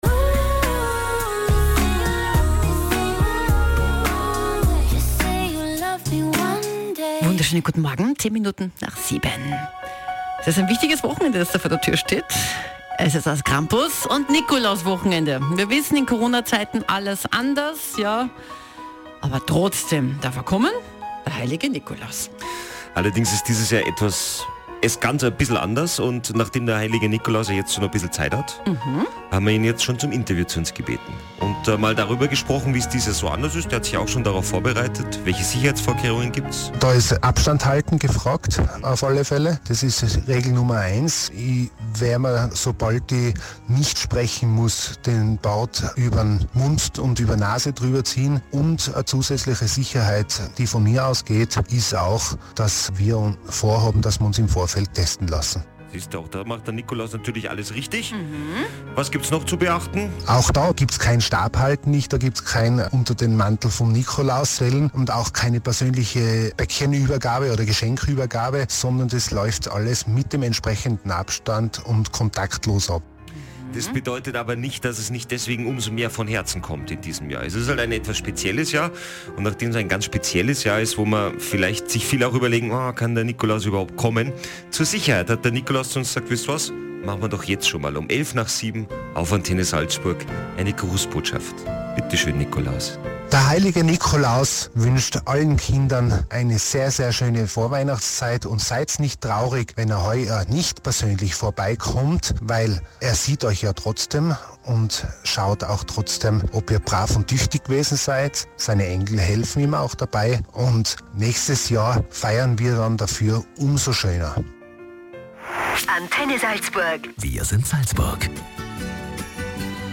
Unter diesem Titel haben wir uns dazu entschlossen, gemeinsam mit der Antenne Salzburg viele Advents- und Weihnachtsbräuche ins Radio zu bringen, um diese vielen Familien und jungen Salzburgerinnen und Salzburgern näher zu bringen.
Mitschnitt_Nikolaus.mp3